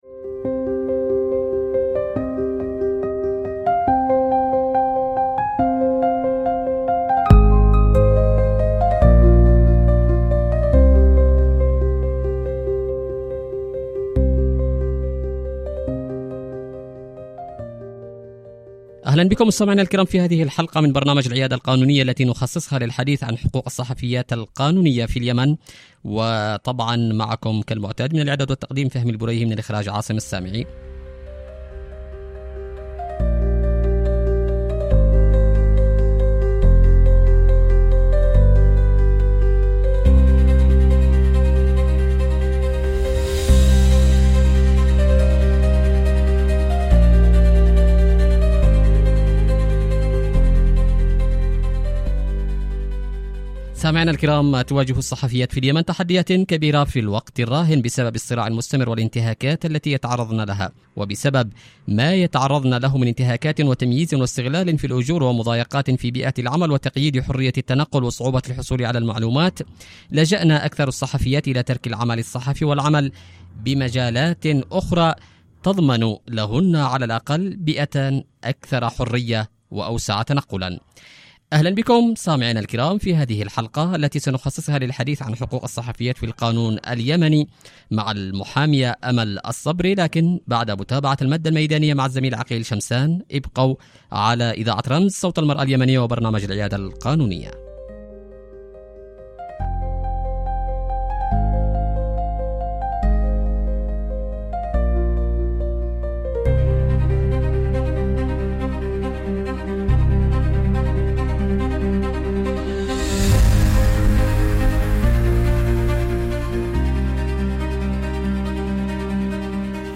في نقاش قانوني يتناول واقع الصحفيات في اليمن، بين ما يكفله القانون من حماية وحقوق، وما يعيقهن من ممارسات أو تحديات مهنية ومجتمعية.